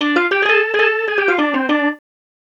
Swinging 60s 3 Organ Lk-D.wav